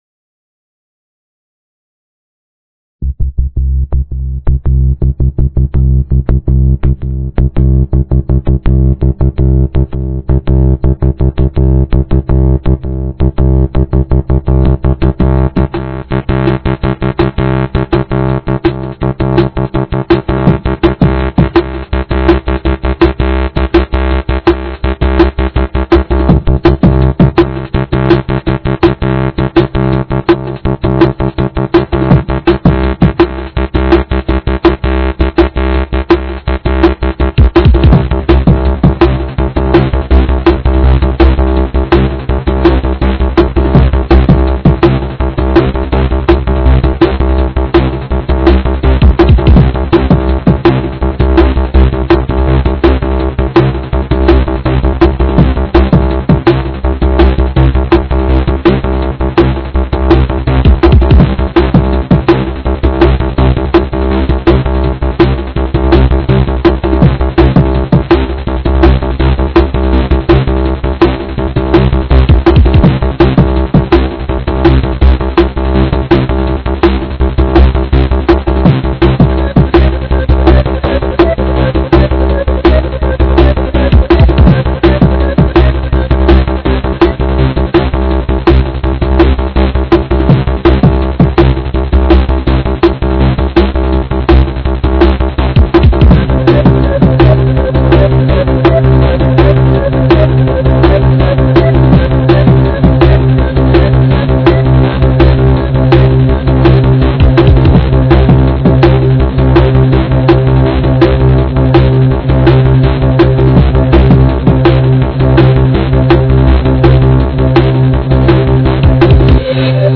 dance/electronic
House
Techno
Drum & bass